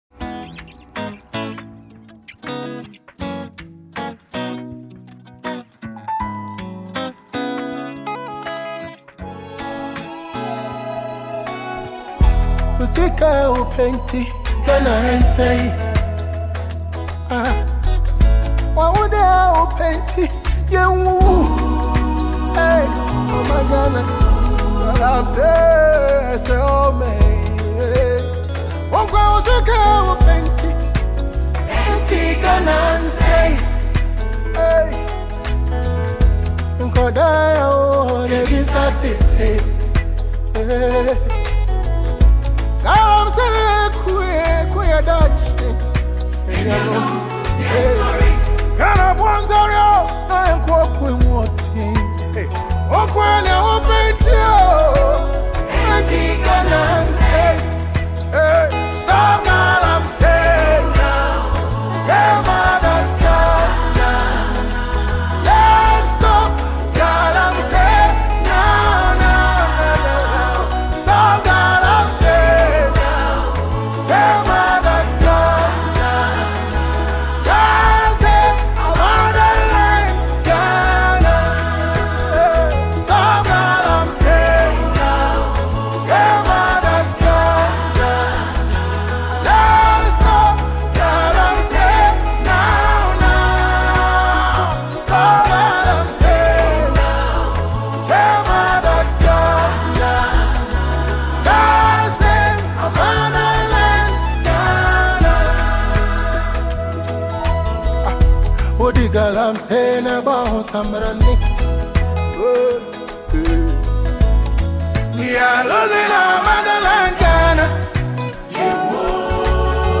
Ghanaian gospel musician
With his soulful vocals and thought-provoking lyrics